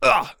ZS受伤3.wav
ZS受伤3.wav 0:00.00 0:00.41 ZS受伤3.wav WAV · 35 KB · 單聲道 (1ch) 下载文件 本站所有音效均采用 CC0 授权 ，可免费用于商业与个人项目，无需署名。
人声采集素材/男3战士型/ZS受伤3.wav